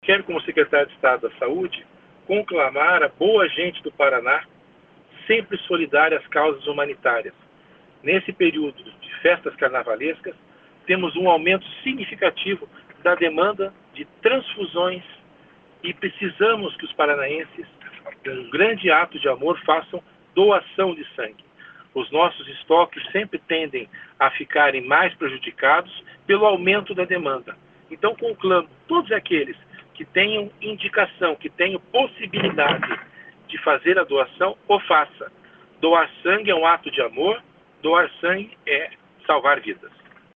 Sonora do secretário estadual da Saúde, César Neves, sobre os horários especiais de atendimento no Hemepar para doações de sangue no Carnaval